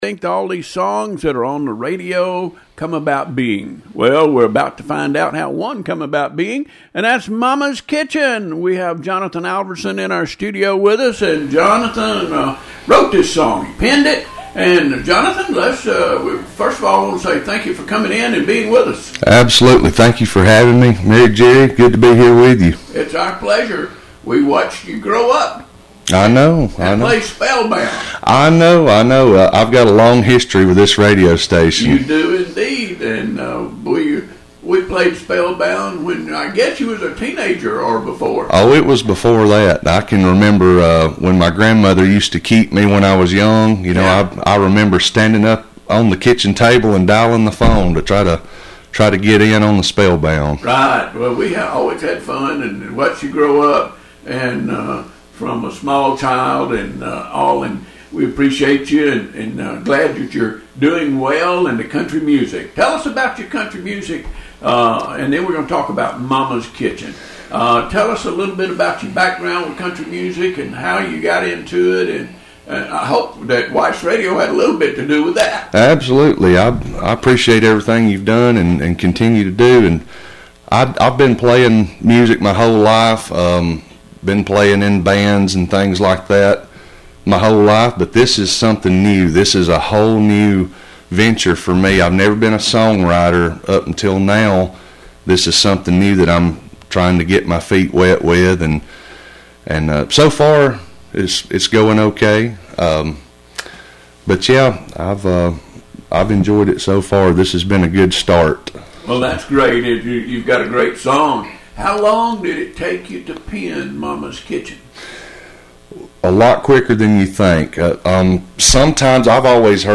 During WEIS Studio Visit